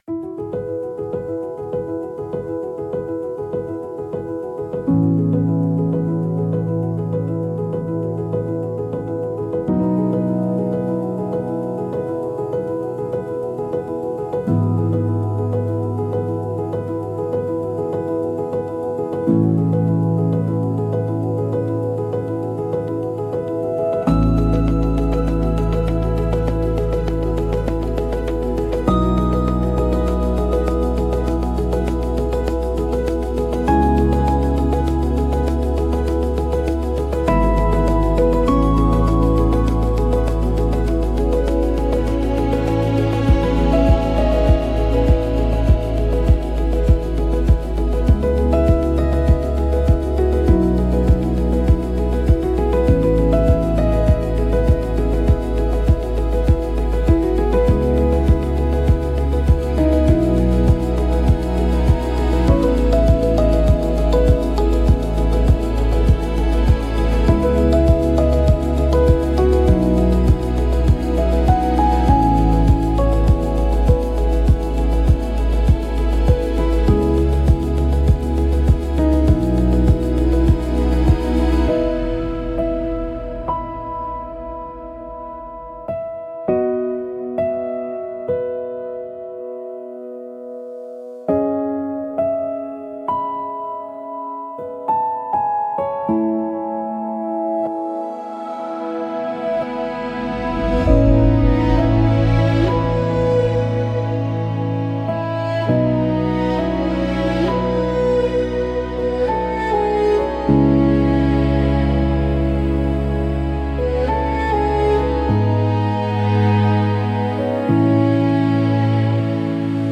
Sound Design-Audio Assets